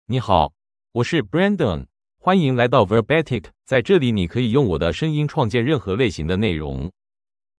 Brandon — Male Chinese AI voice
Brandon is a male AI voice for Chinese (Mandarin, Traditional).
Voice sample
Male
Brandon delivers clear pronunciation with authentic Mandarin, Traditional Chinese intonation, making your content sound professionally produced.